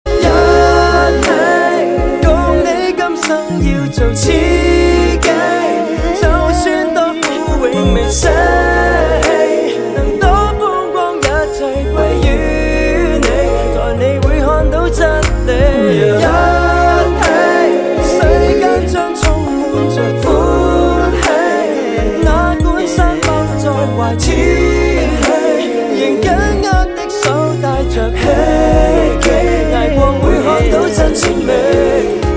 M4R铃声, MP3铃声, 华语歌曲 39 首发日期：2018-05-15 19:38 星期二